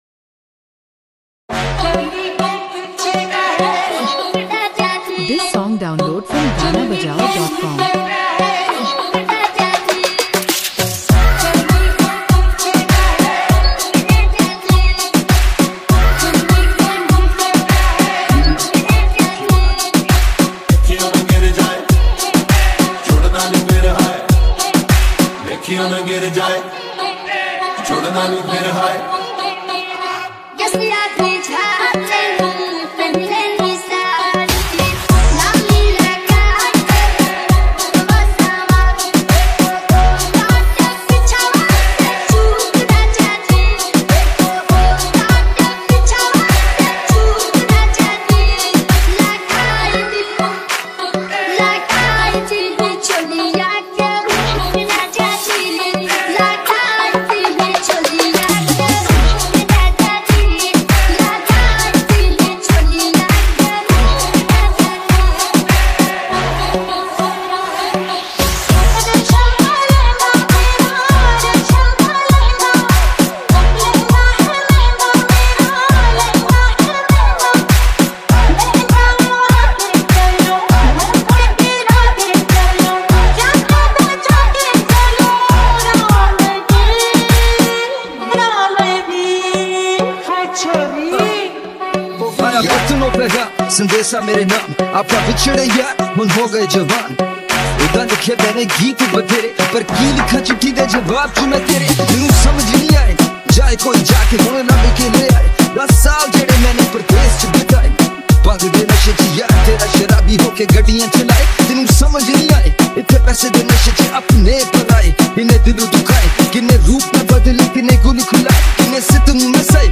Item Song Mashup